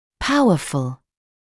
[‘pauəfl][‘пауэфл]сильный, мощный